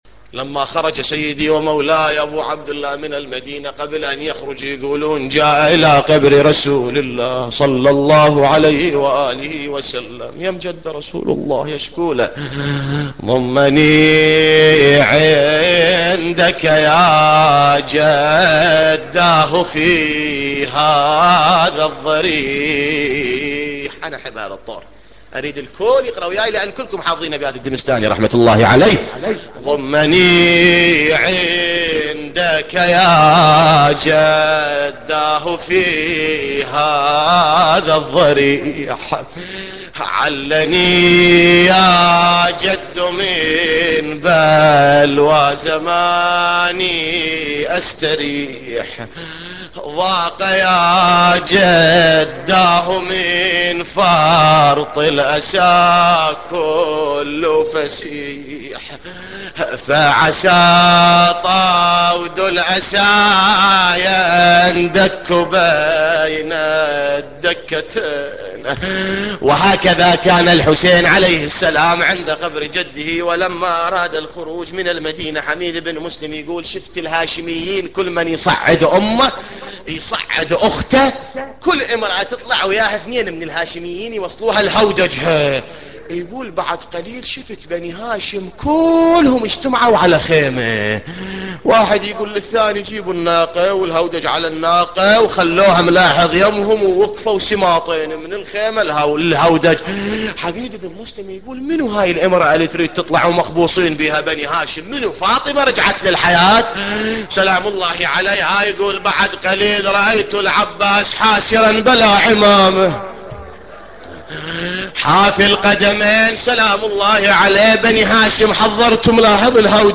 نواعي حسينية 13